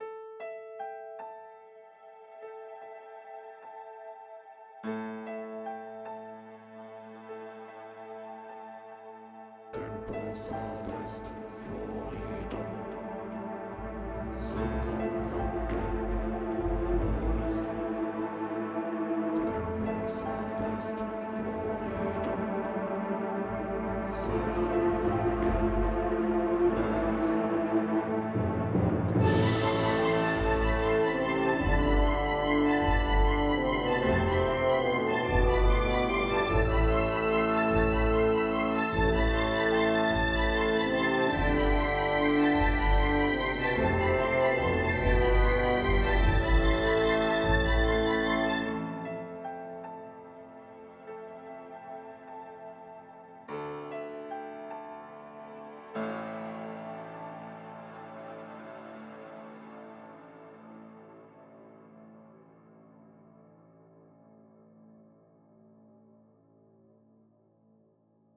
Dies war einer meiner ersten Versuche, klassische Musik zu machen. Keine Ahnung, was das lateinische Zeugs da ist, was ich labere.